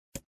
menu-multiplayer-hover.ogg